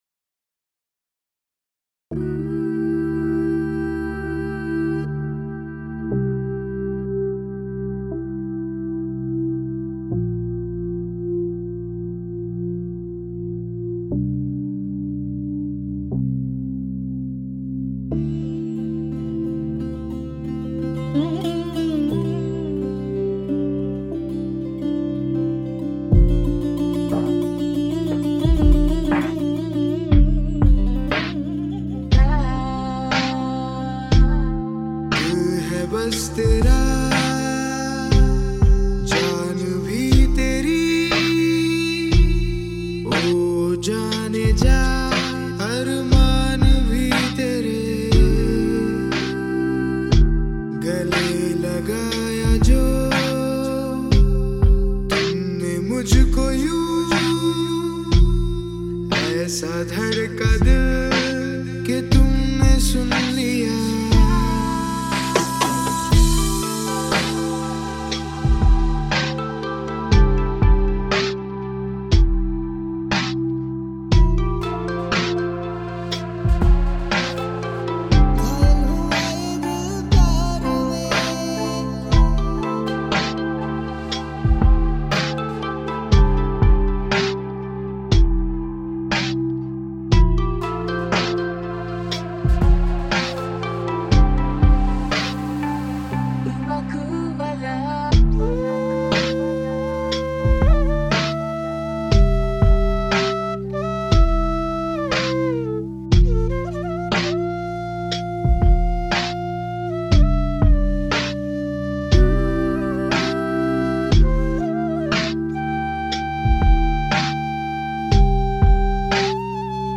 mp3,5211k] Фолк